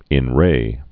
(ĭn rā, rē)